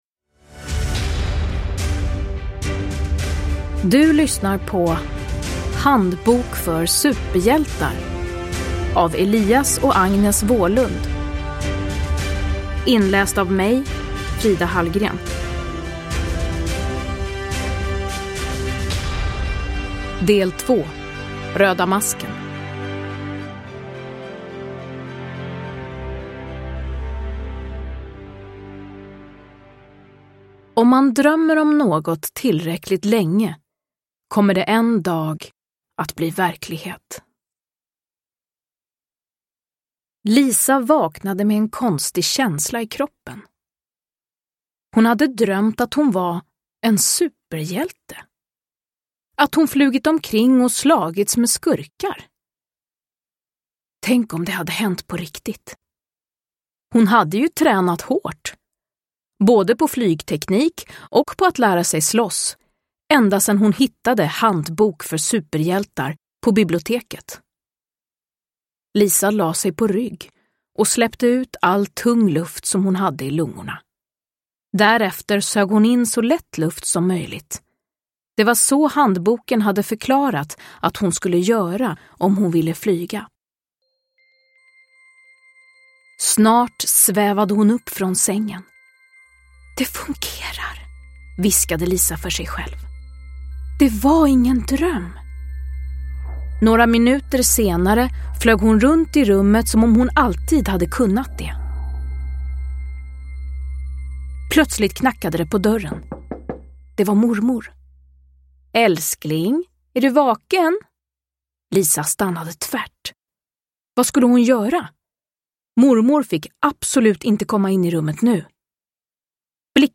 Handbok för superhjältar. Röda masken – Ljudbok – Laddas ner
Uppläsare: Frida Hallgren